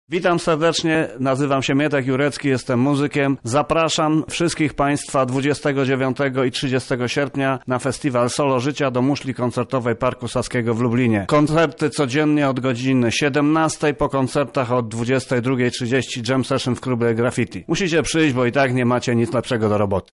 O festiwalu mówi Mietek Jurecki organizator i pomysłodawca: